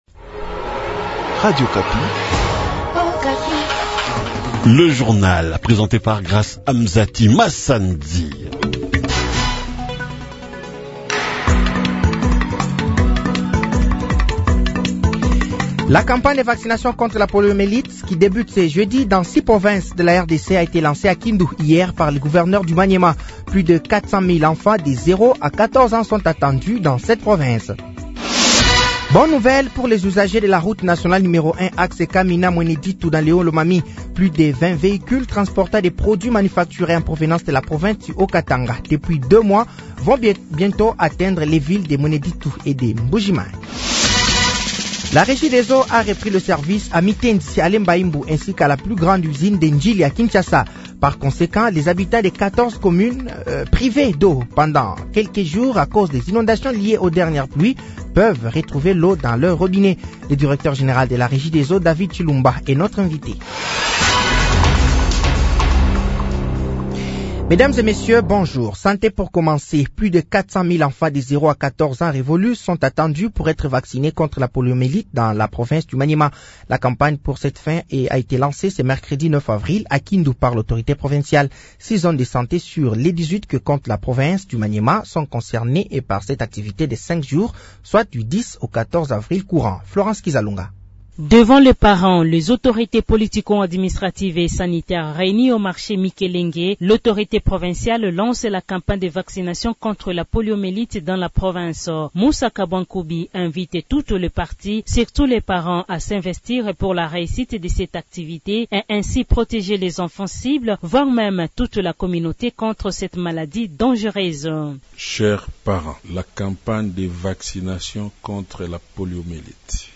Journal français de 08 de ce jeudi 10 avril 2025